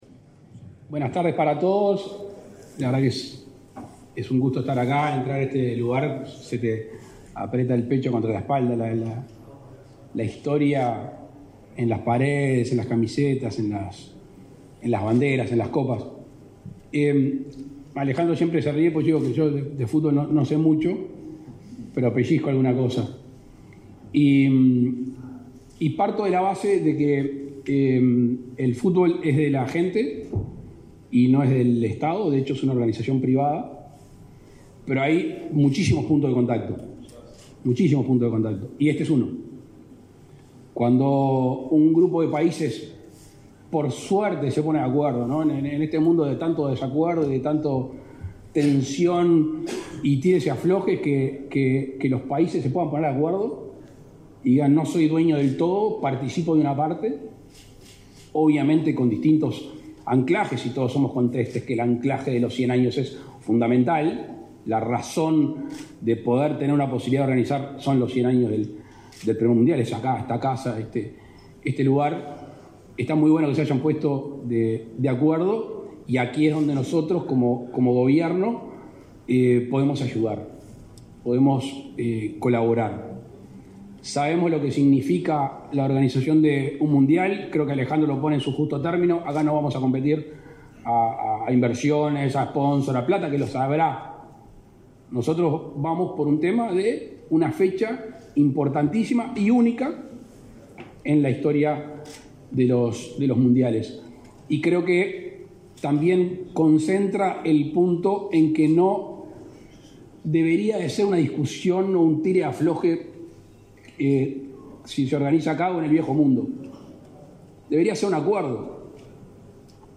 Palabras del presidente de la República, Luis Lacalle Pou
Con la presencia del presidente de la República, Luis Lacalle Pou, la Asociación Uruguaya de Fútbol realizó, este 2 de agosto, el acto de lanzamiento